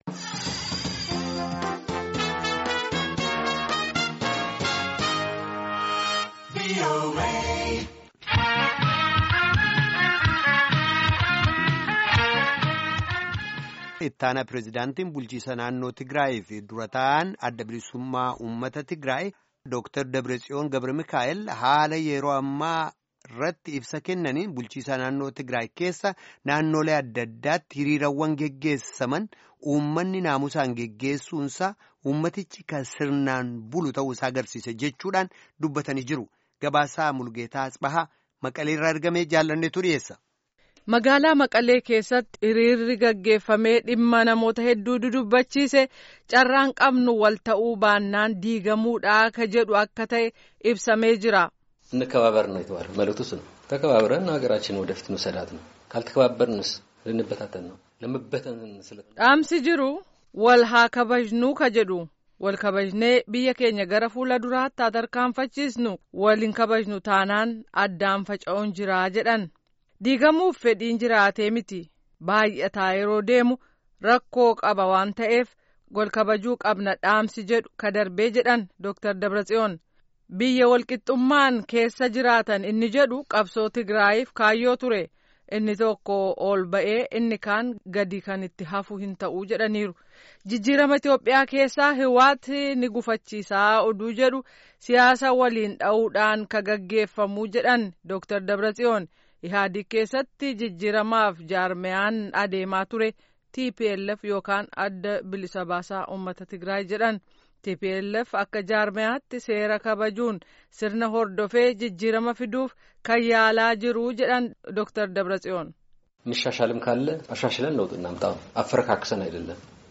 Gabaasaa